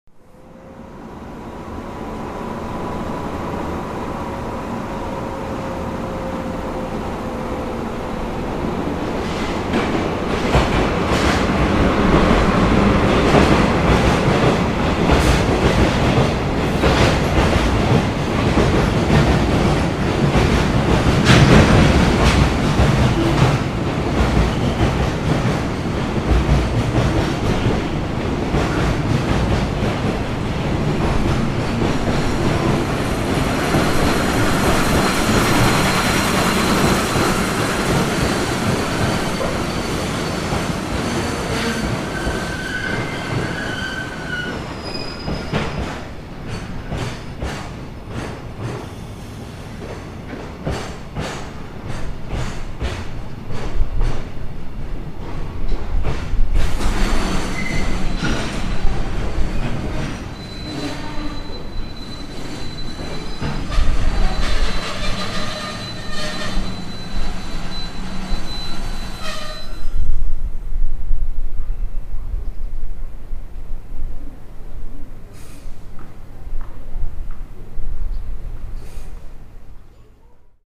平成２０年７月１３日　関西線平野駅に停車するコンテナ列車
DD51がやってくるのが見えたので慌ててホームでマイクをセットすると、見事にマイクの反対側の線路に入ってきました。
後ろで聴こえている音です。（＞＜）　久々に鉄の音を聴いた気分になりました。０８・０７・１３追加